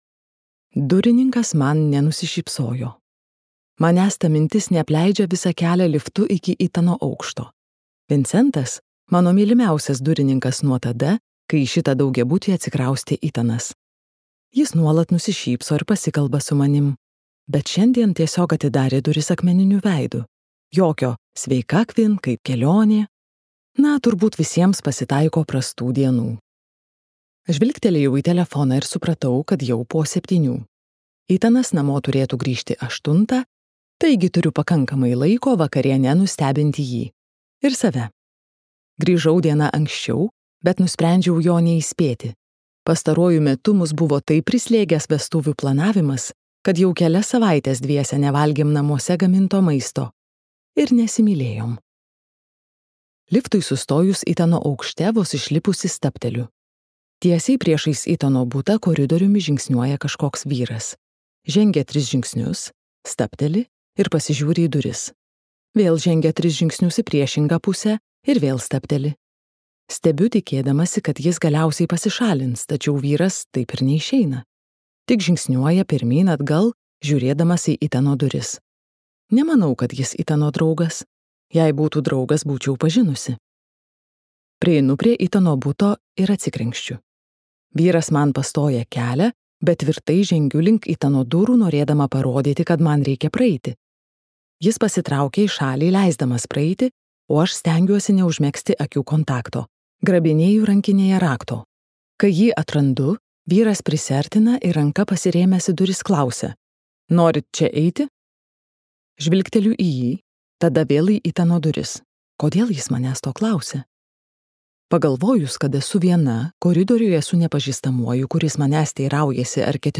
Ką praleidau, kol miegojai | Audioknygos | baltos lankos